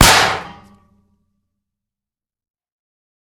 3098b9f051 Divergent / mods / JSRS Sound Mod / gamedata / sounds / material / bullet / collide / metal05gr.ogg 64 KiB (Stored with Git LFS) Raw History